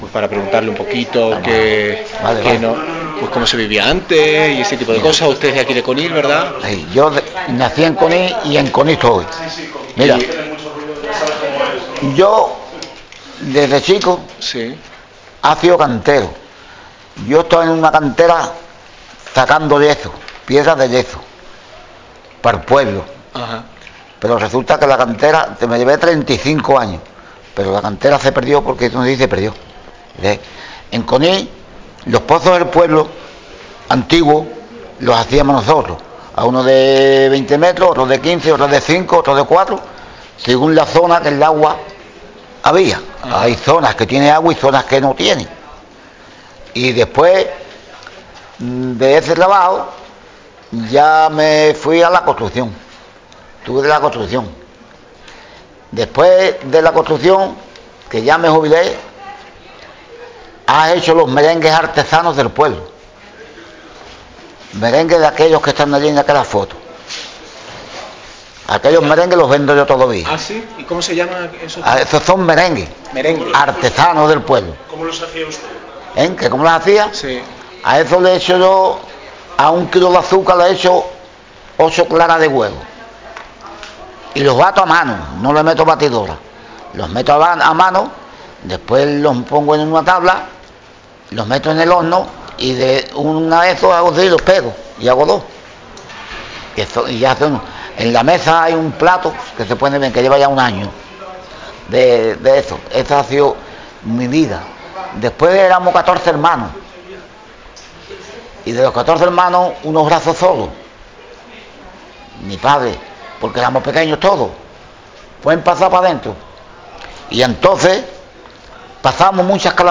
Enclave Conil de la Frontera
Informantes I1:�var�n�-�85�a�os� Temas 1. Matanza del cerdo 2. Alimentaci�n 3. Animales dom�sticos 4. Labores del hogar 6. Agricultura 7. Ganader�a